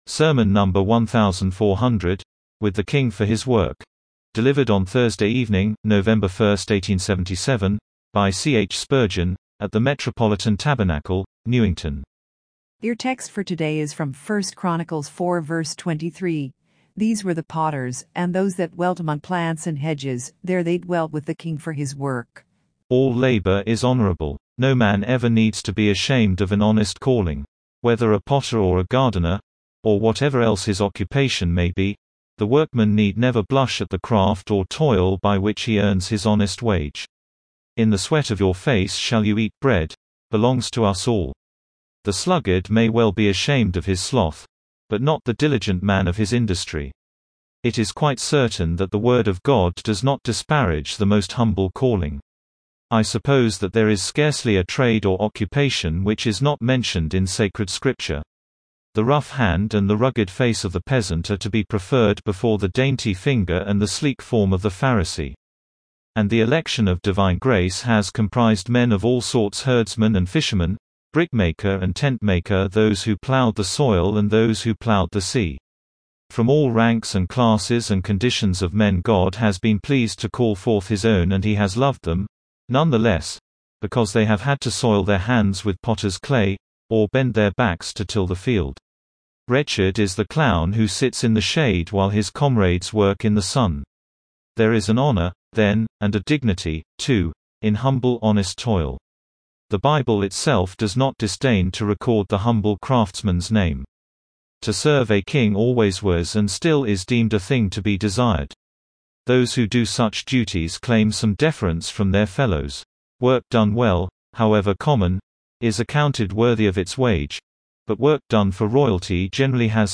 Sermon number 1,400, WITH THE KING FOR HIS WORK!